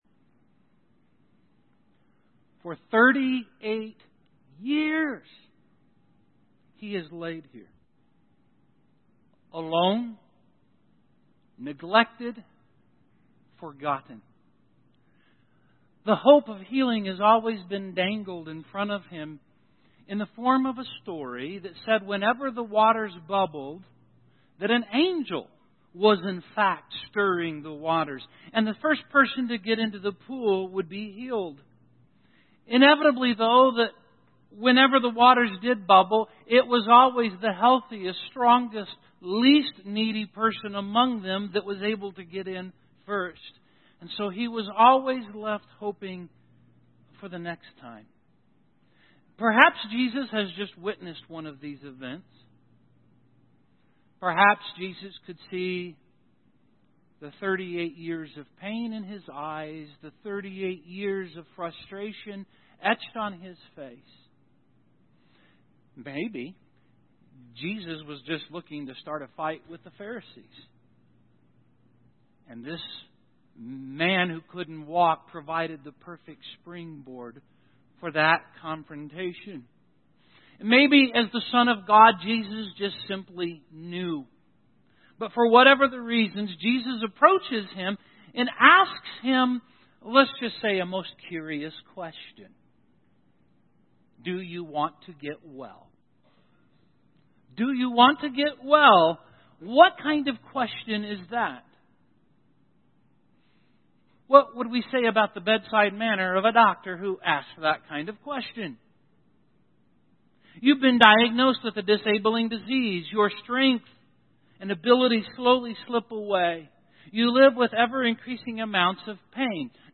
Sin John Seven Signs Audio Sermon Save Audio Save PDF Imagine a doctor going into a hospital filled with sick patients.